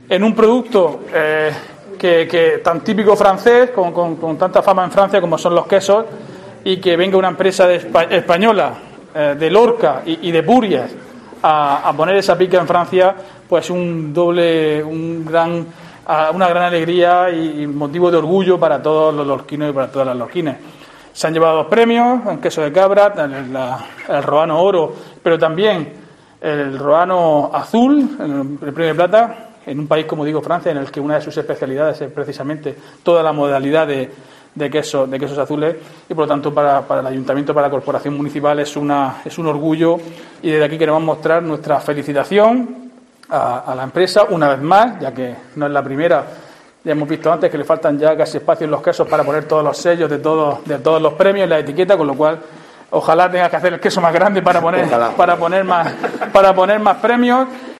Diego José Mateos, alcalde de Lorca sobre Quesos Roano